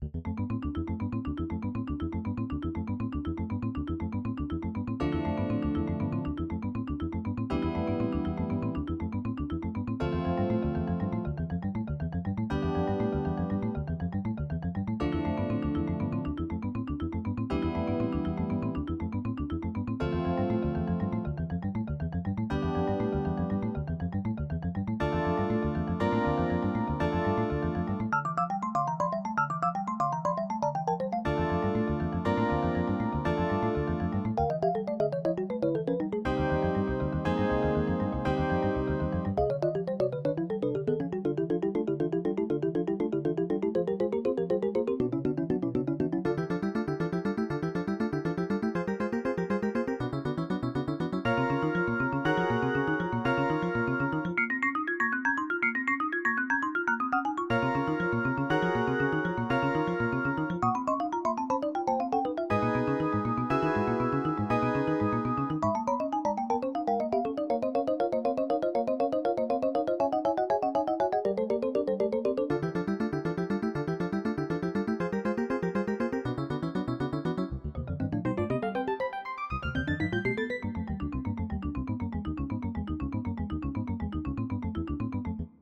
Electronic / 2009